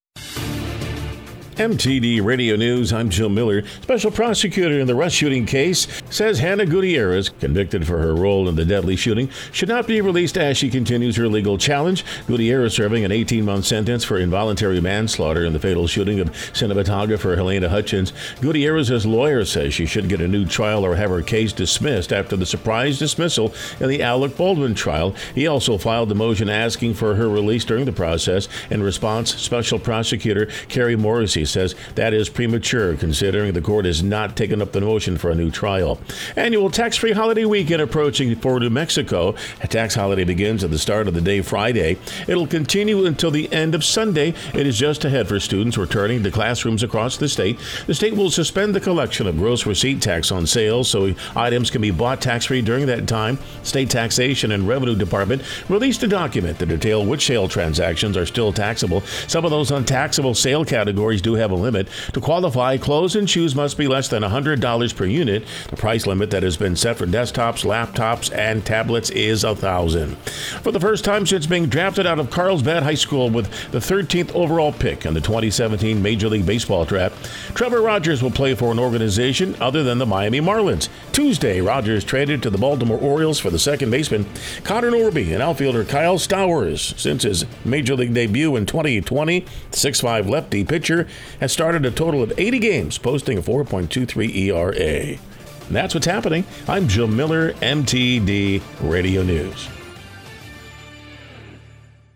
W105 NEWS SE NEW MEXICO AND WEST TEXAS